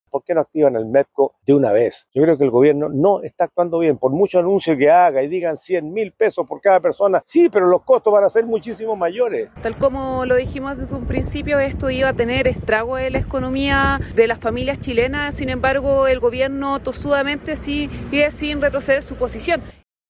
En esa línea, el senador Iván Flores (DC) propuso directamente reactivar el MEPCO, mientras que la diputada Daniela Serrano (PC) cuestionó la rigidez que ha mostrado la administración actual frente a las demandas sociales.